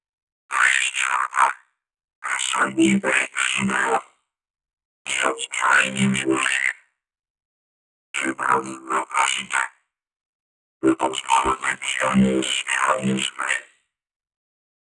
futuristic-evil-robot-voi-fmeknq7i.wav